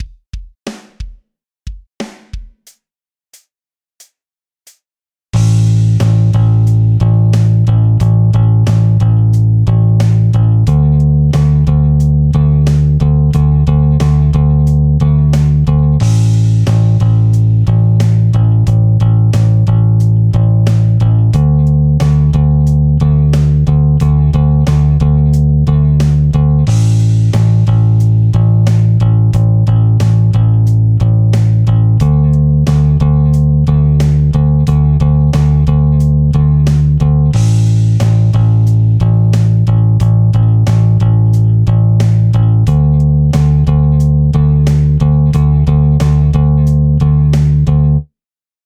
5．開放弦とタイを使用したベース練習フレーズ7選！
4．4分音符と8分音符にタイが含まれる練習フレーズ
4分音符と8分音符が混ざっていて、リズム感を保つのが難しい。